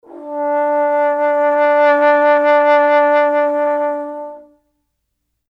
interactive-fretboard / samples / trombone / D4.mp3